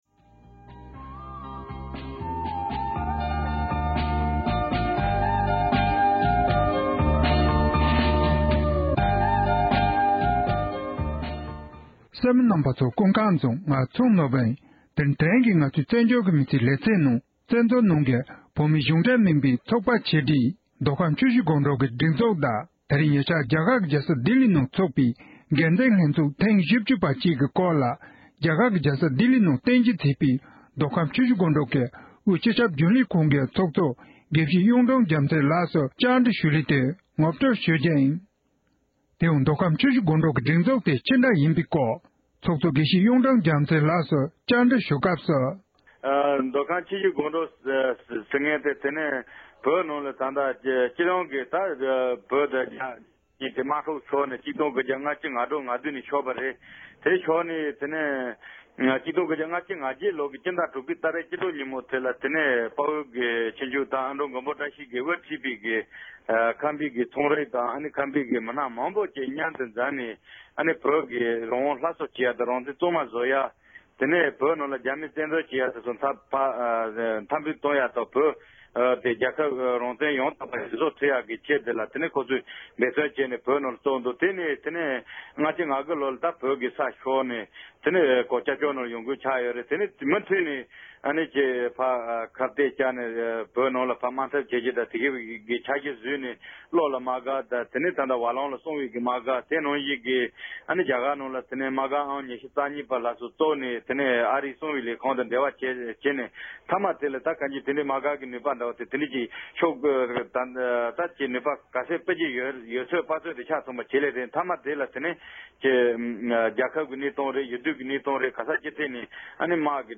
མདོ་ཁམས་ཆུ་བཞི་སྒང་དྲུག་གི་འགན་འཛིན་ལྷན་ཚོགས་ཐེངས་བཞི་བཅུ་པའི་སྐོར་གླེང་མོལ།